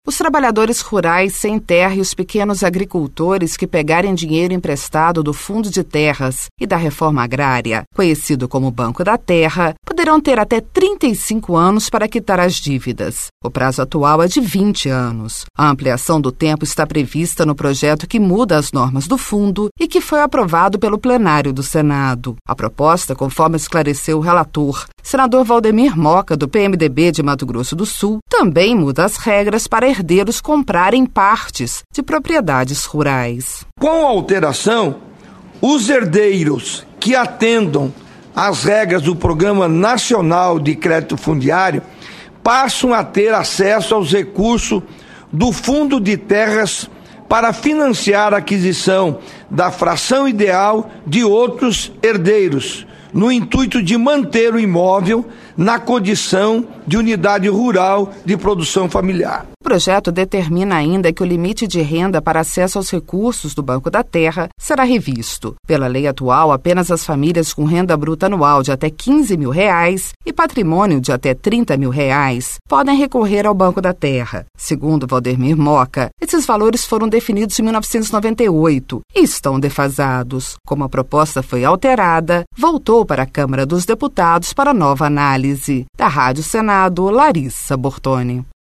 Senador Waldemir Moka